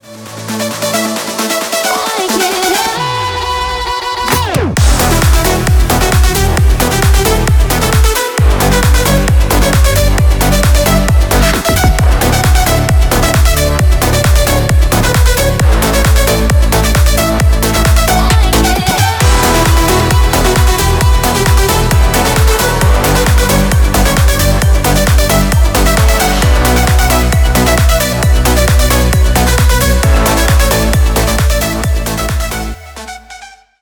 Танцевальные # без слов